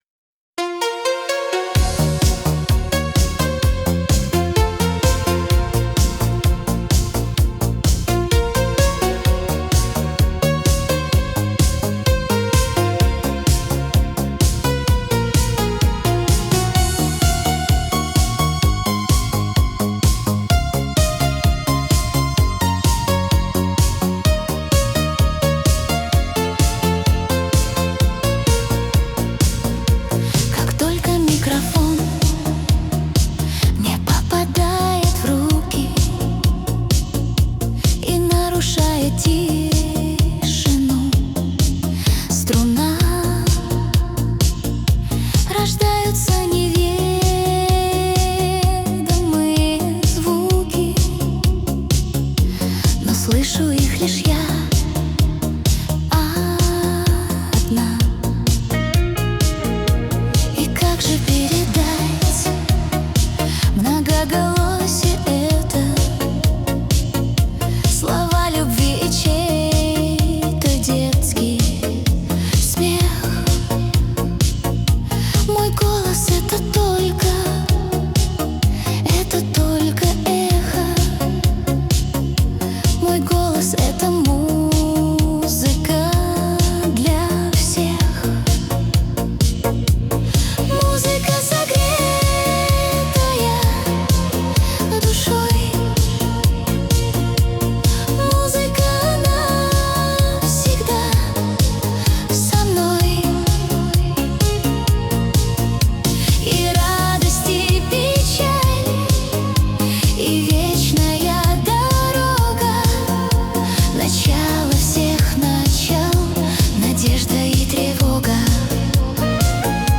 AI кавер на песню группы